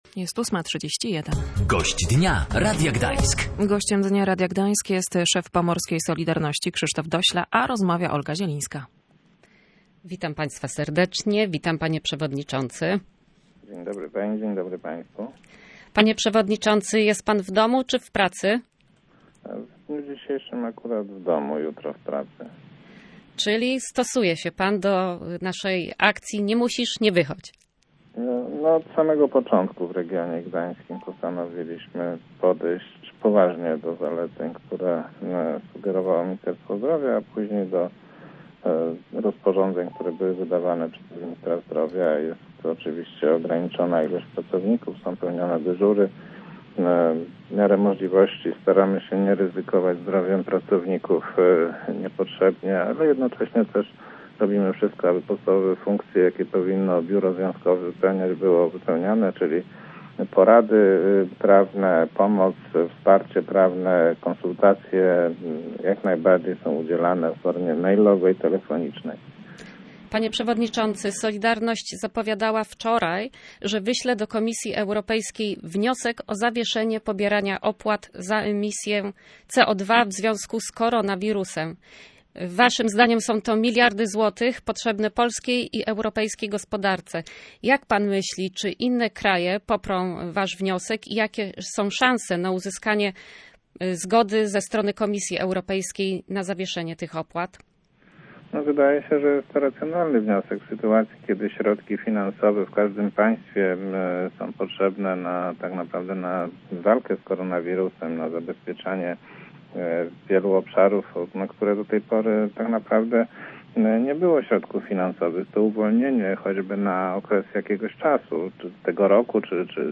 Podczas rozmowy poruszono także kwestię tarczy antykryzysowej.